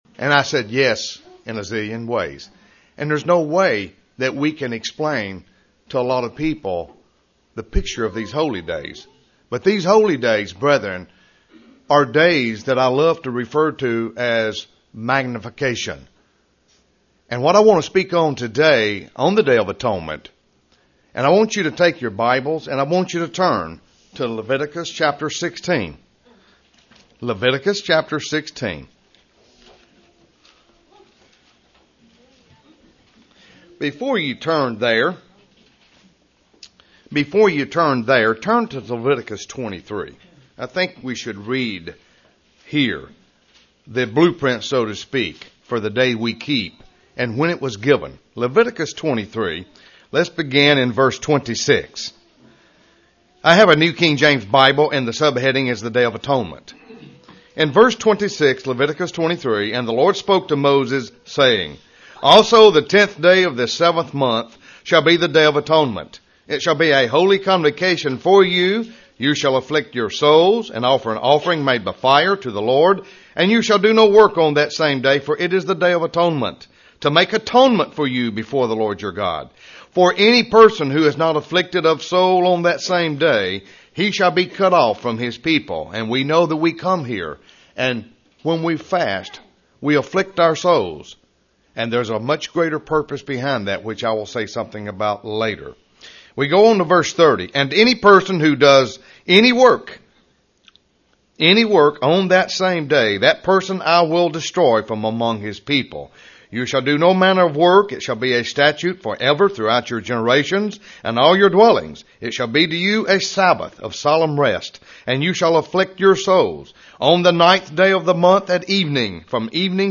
Given in Ft. Lauderdale, FL
UCG Sermon Studying the bible?